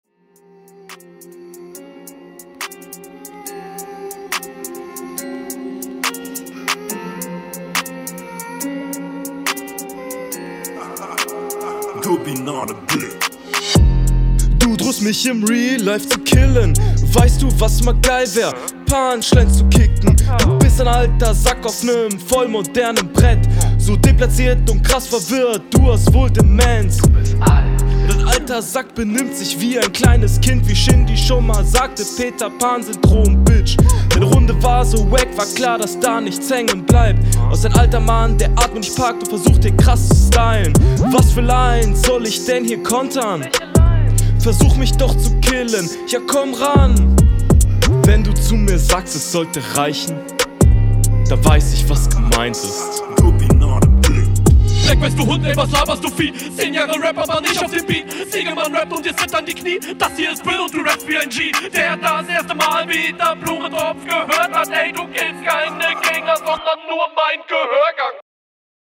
Flow: Flow ist vieles aber nicht standard, die Betonungen sind zwar teilweise nicht Flow fördernd …
du hast mehr Probleme mit dem Takt als dein Gegner aber finde deine Patterns cooler …
Flow: trifft den Takt, Betonungen sehr passend und mehr on Point, aber dafür nicht ganz …